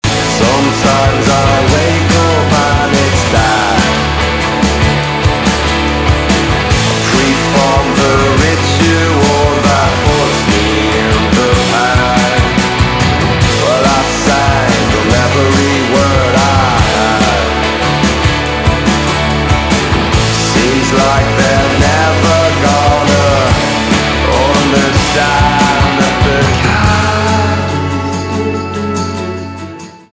инди
гитара , барабаны , качающие